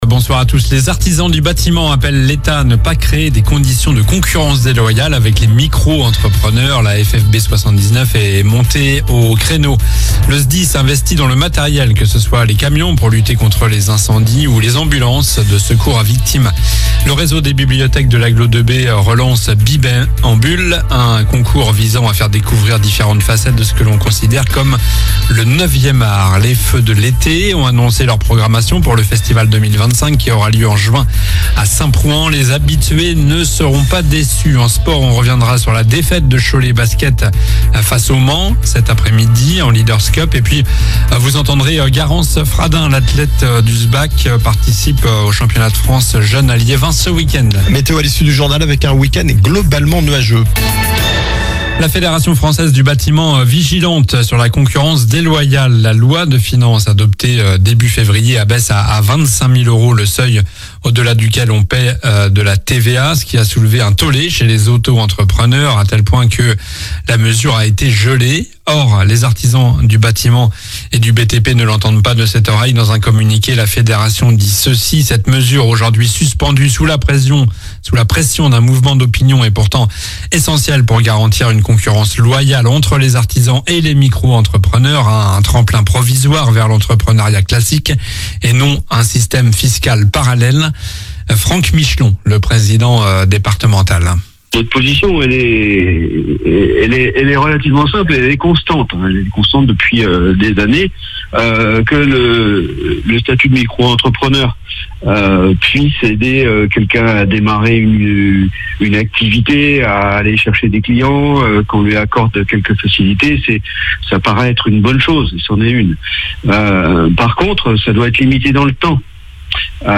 Journal du vendredi 14 février (soir)